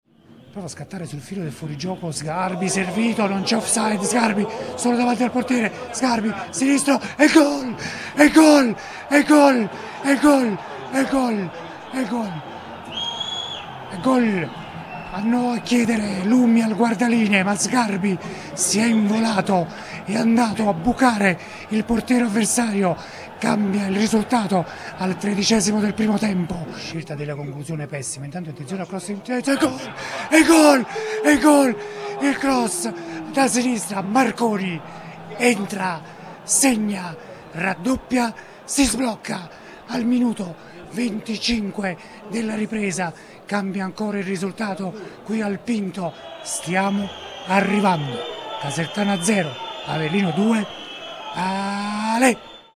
Gol Casertana-Avellino 0-2 con la Radriocronaca
in occasione dei gol di Casertana-Avellino, nel corso della radiocronaca su Radio Punto Nuovo.